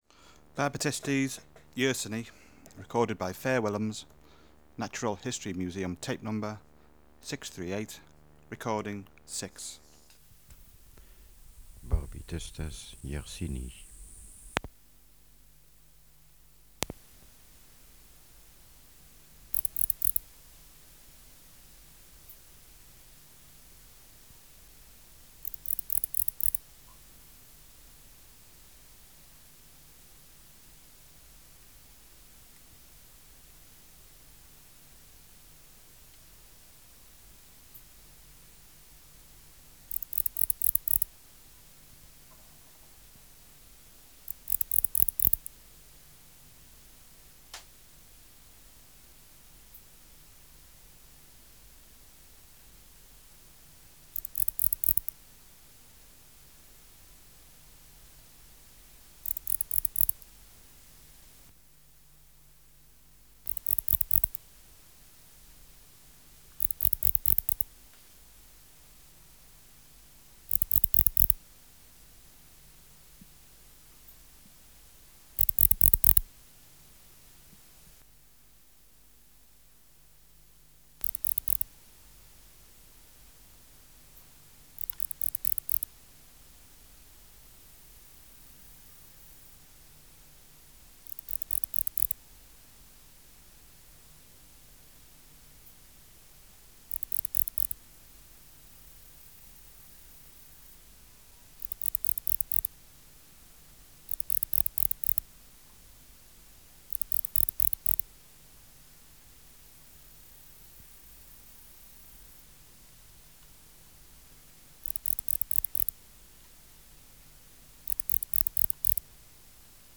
Air Movement: Nil
Another (silent) male present in same cage
Microphone & Power Supply: AKG D202E (LF circuit off) Distance from Subject (cm): 8